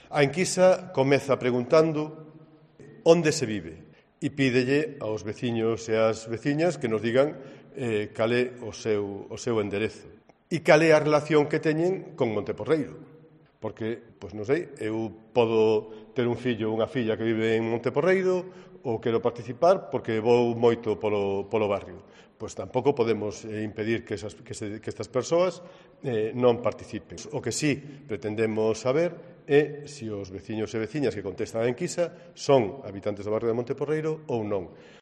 El concejal Demetrio Gómez, sobre la encuesta para el barrio de Monte Porreiro